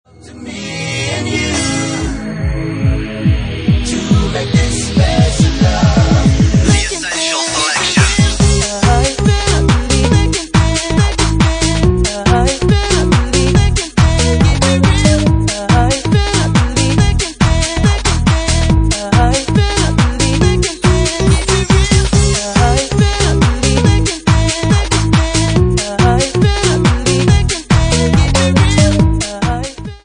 Bassline House at 141 bpm